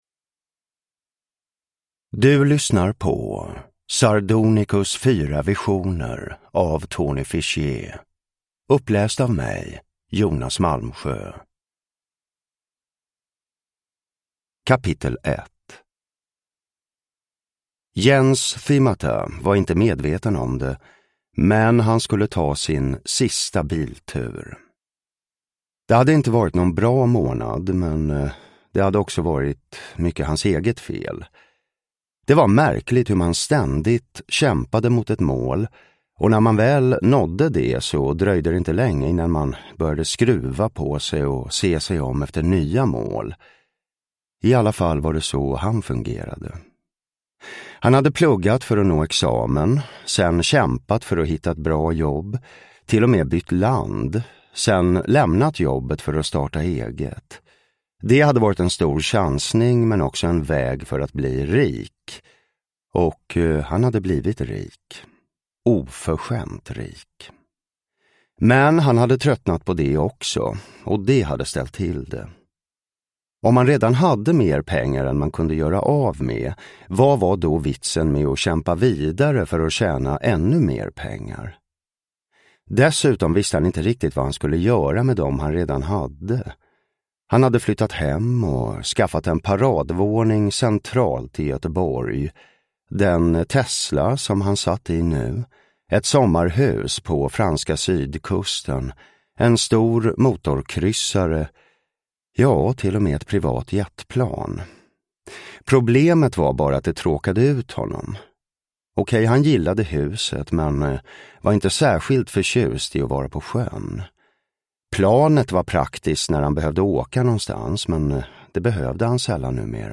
Uppläsare: Jonas Malmsjö
Ljudbok